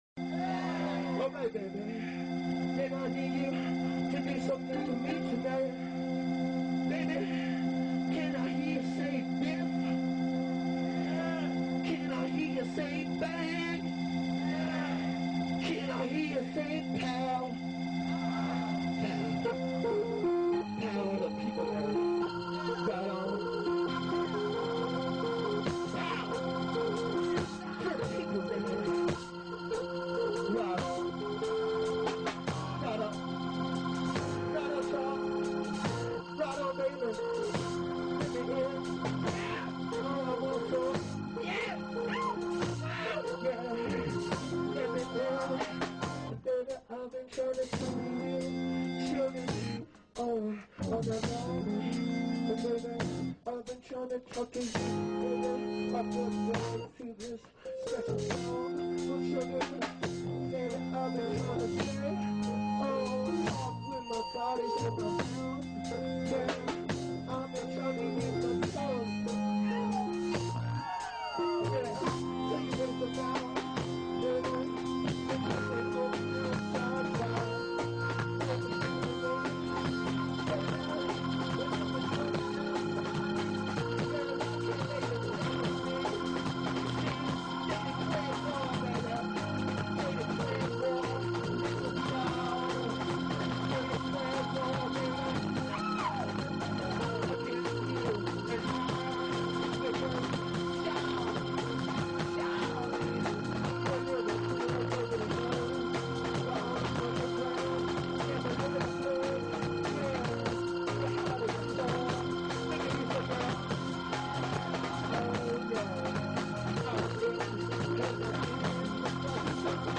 live in Prague 1998